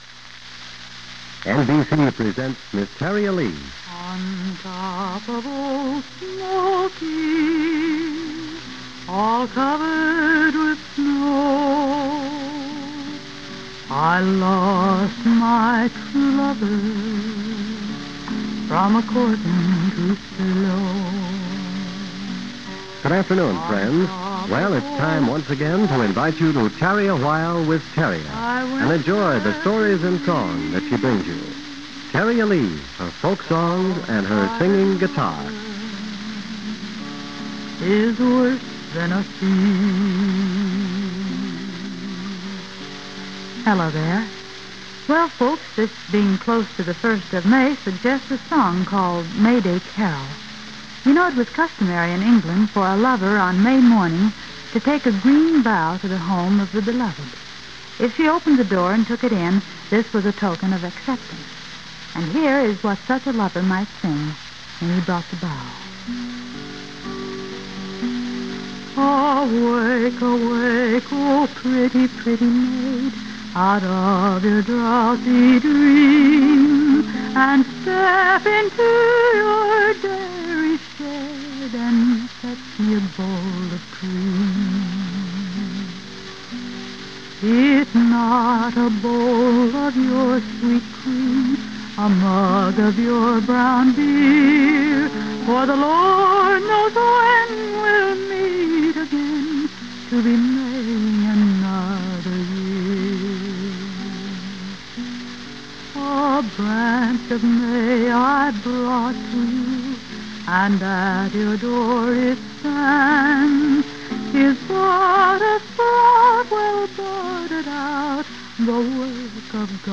folksinger